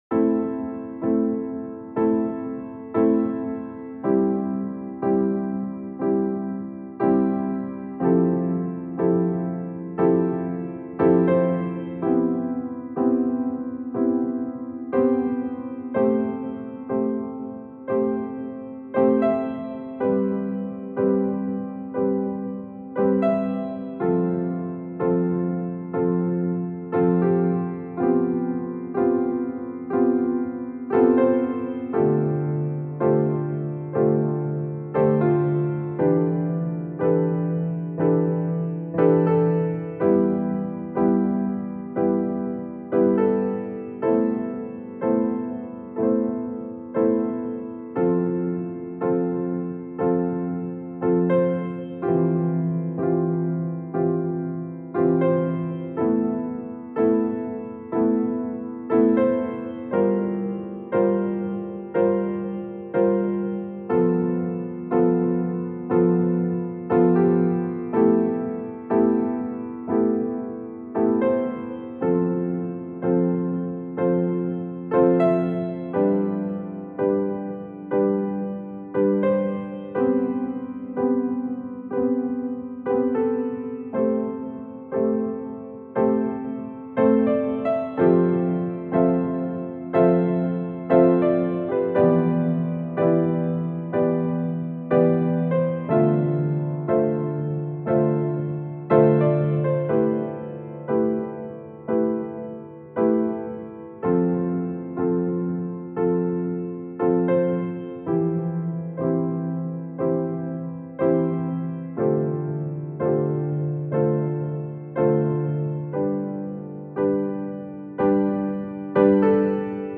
ピアノ 幻想的 悲しい 暗い 穏やか